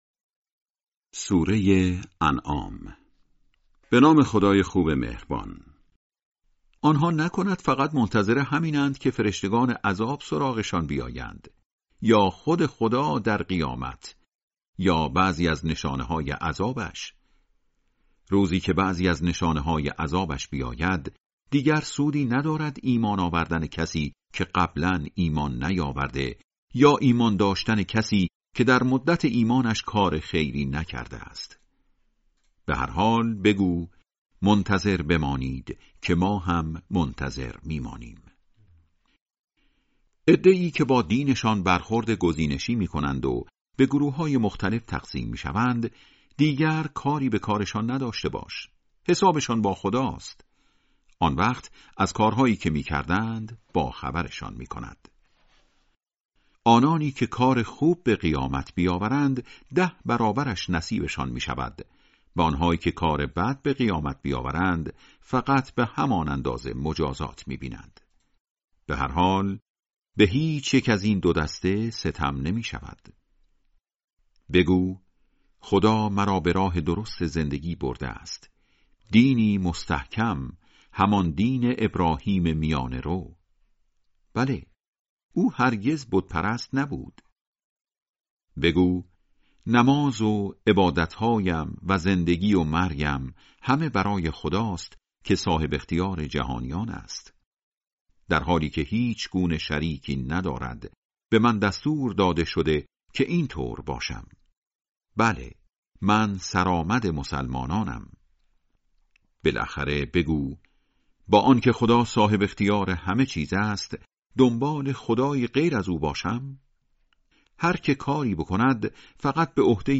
ترتیل صفحه ۱۵۰ سوره سوره انعام با قرائت استاد پرهیزگار(جزء هشتم)
ترتیل سوره(انعام)